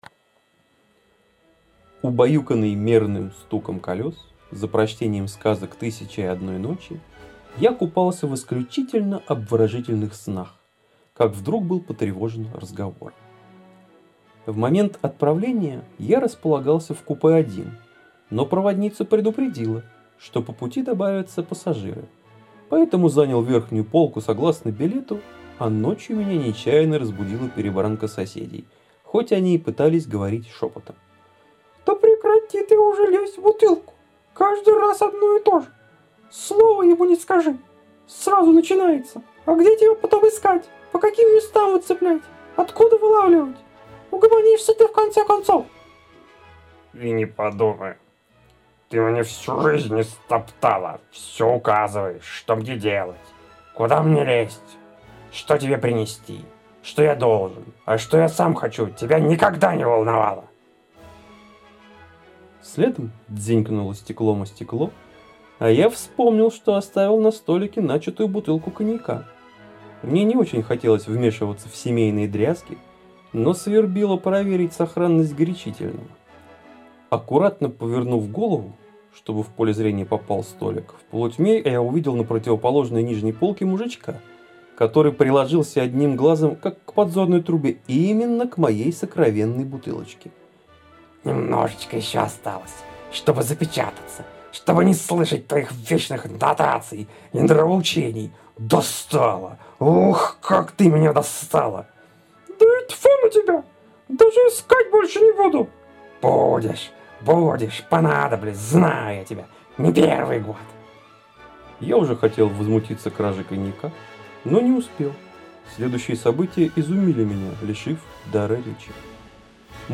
Так они все с легкой фоновой классикой :yep: